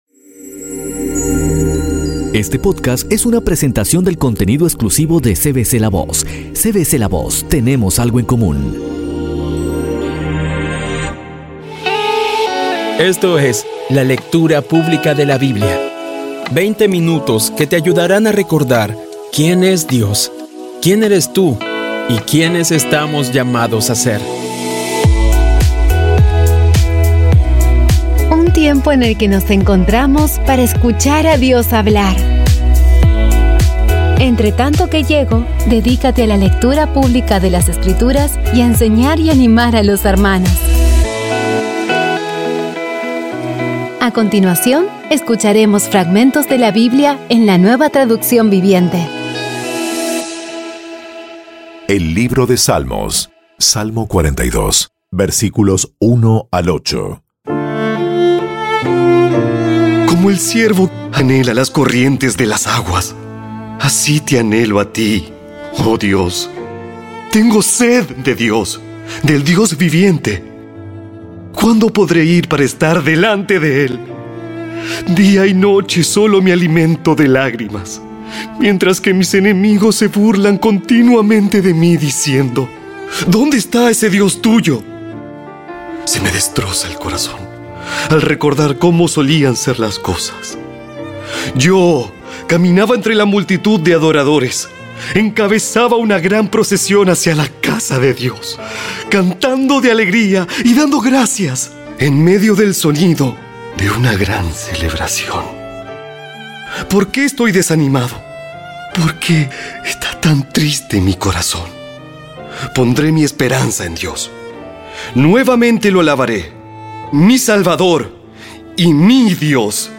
Audio biblia Dramatizada Episodio 93
Poco a poco y con las maravillosas voces actuadas de los protagonistas vas degustando las palabras de esa guía que Dios nos dio.